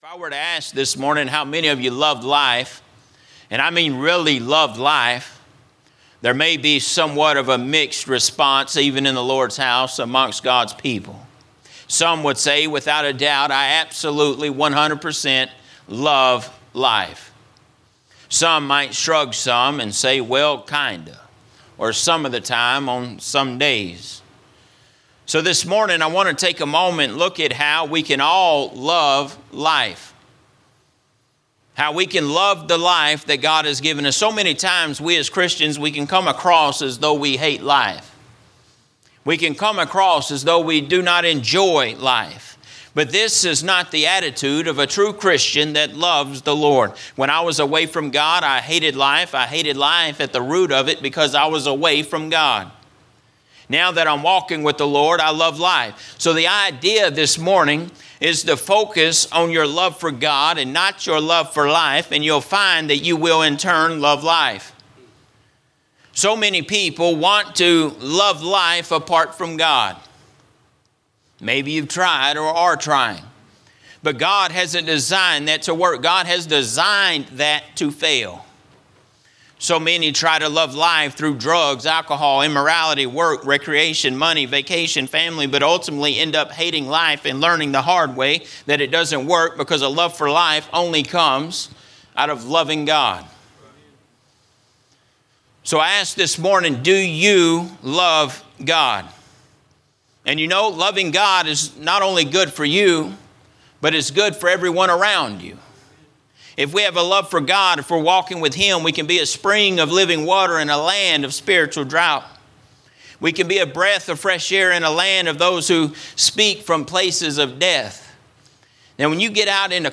A message from the series "General Preaching."